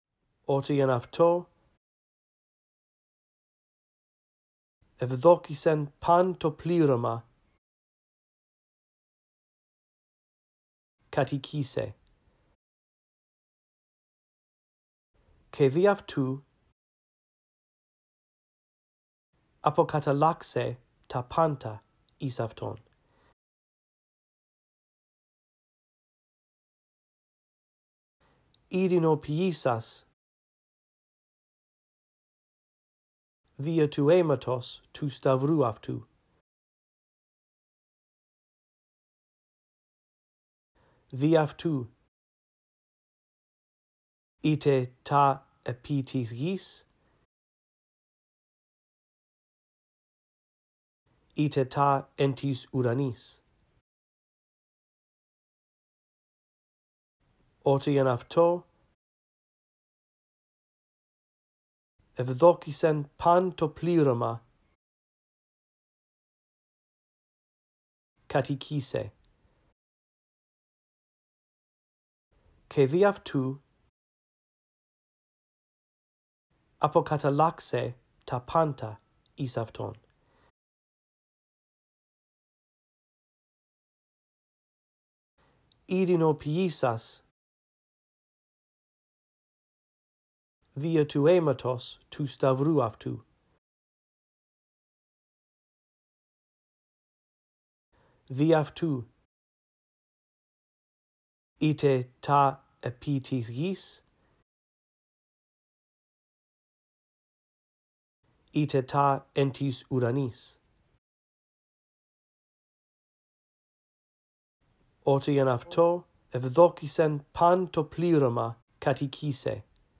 In this audio track, I read through verses 19-20 a phrase at a time, giving you time to repeat after me. After two run-throughs, the phrases that you are to repeat become longer.